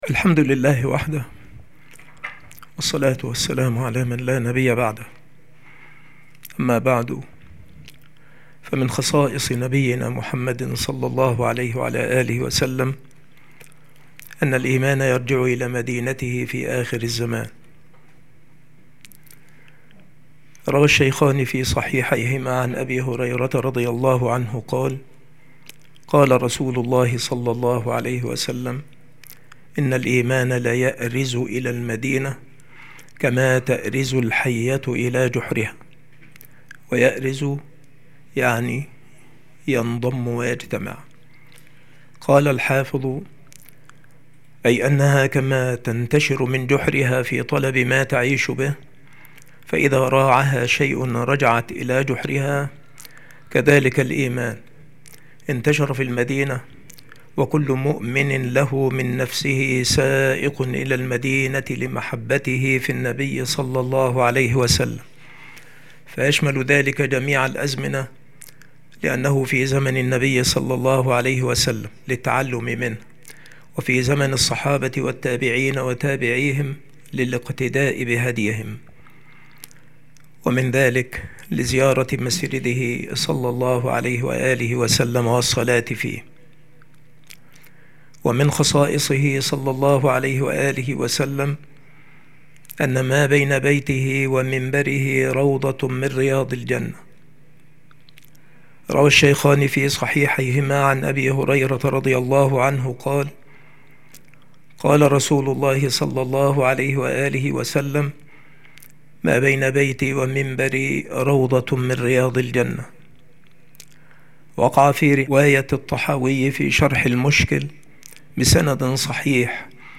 مكان إلقاء هذه المحاضرة المكتبة - سبك الأحد - أشمون - محافظة المنوفية - مصر